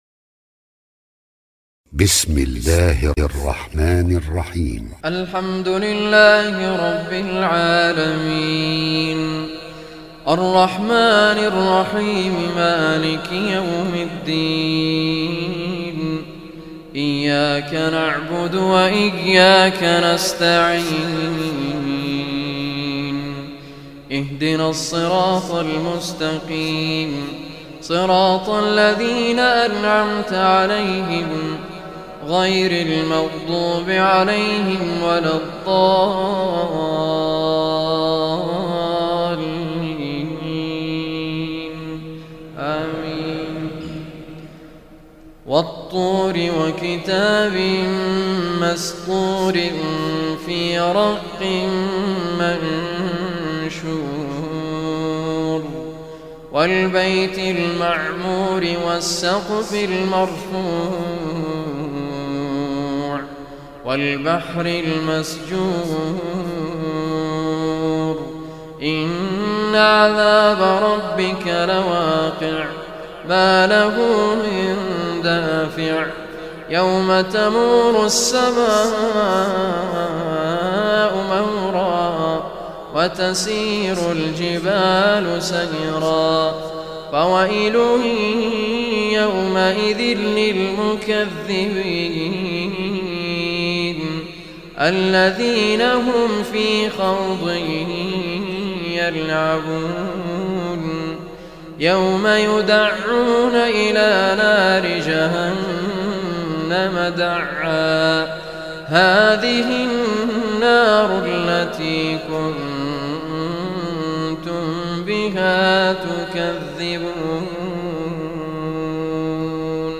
Surah Tur, listen or play online mp3 tilawat / recitation in Arabic in the beautiful voice of Sheikh Raad al Kurdi.